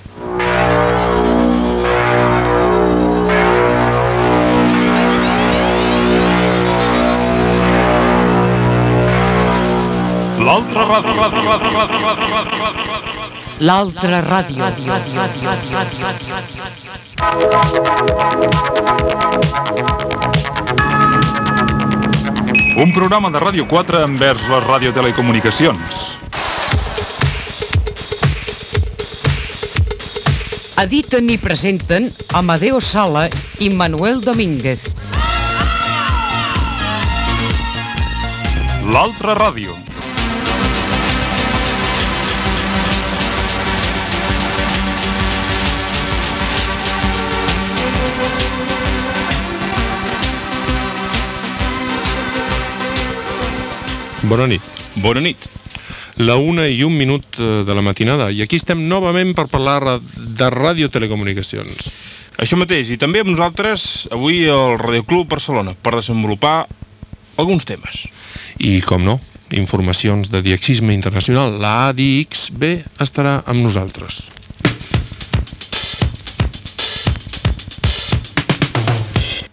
Entrada del programa Gènere radiofònic Divulgació